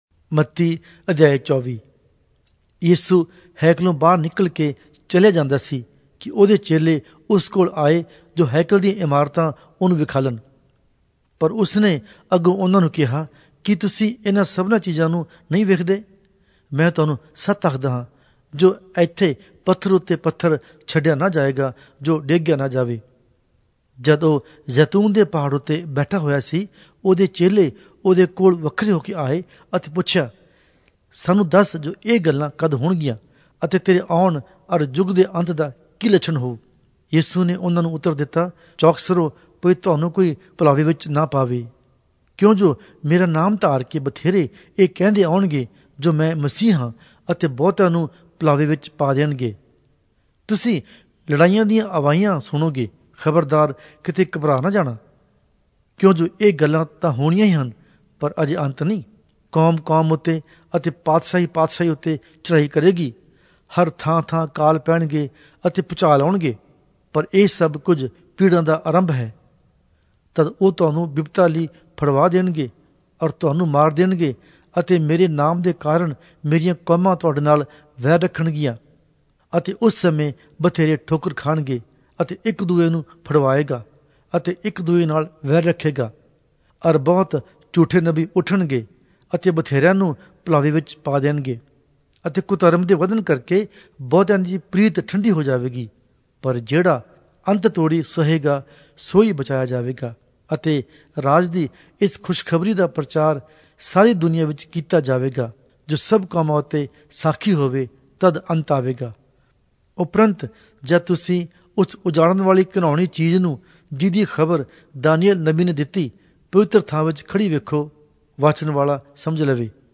Punjabi Audio Bible - Matthew 16 in Ervml bible version